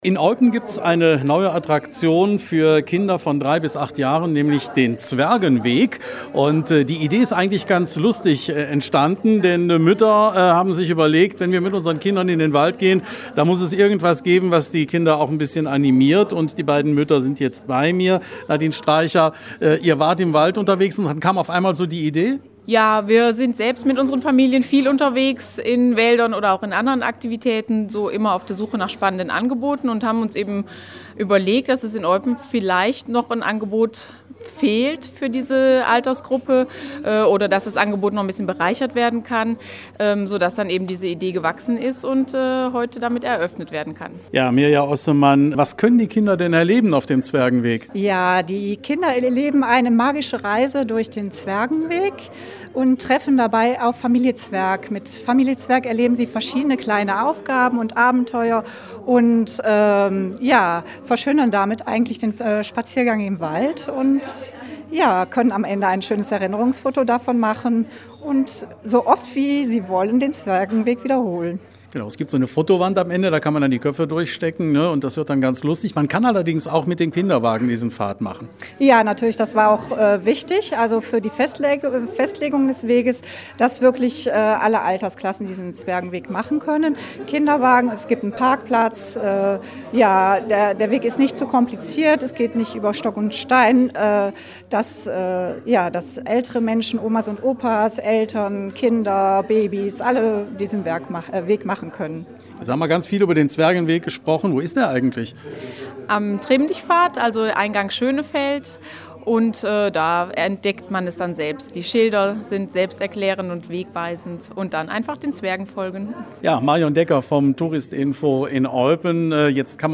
In Eupen gibt es jetzt einen „Zwergenweg“ für Kinder von drei bis acht Jahren. Die Idee dazu hatten zwei junge Mütter.